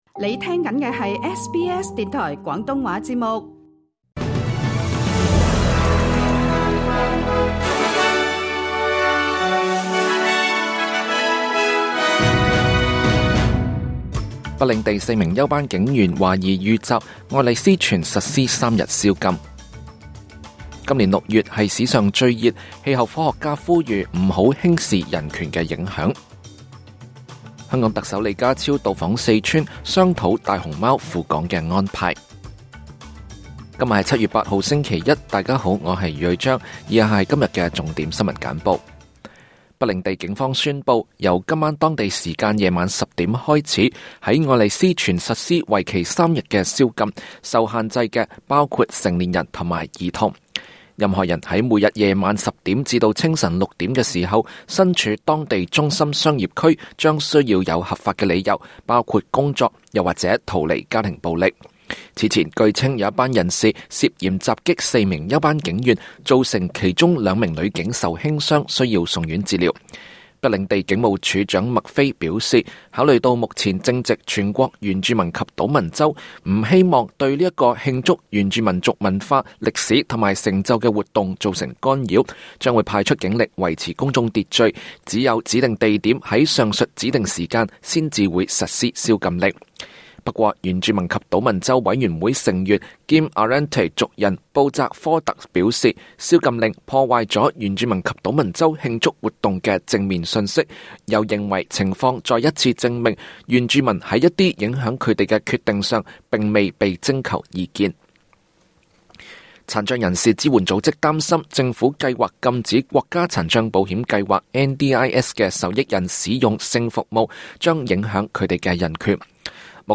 SBS 晚間新聞（2024 年 7 月 8 日）
請收聽本台為大家準備的每日重點新聞簡報。